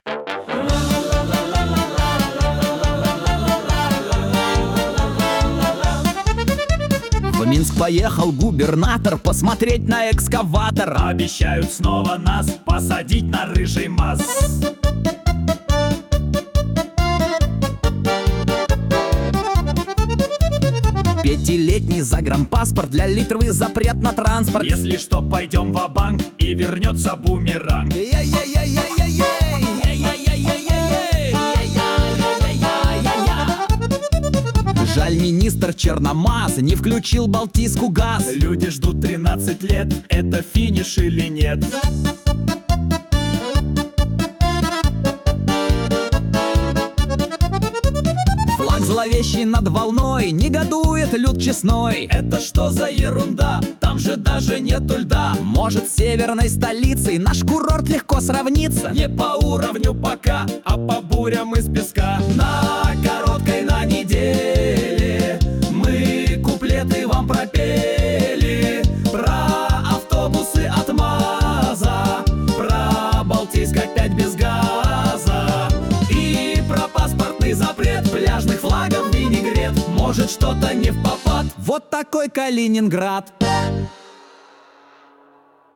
Куплеты на злобу дня о главных и важных событиях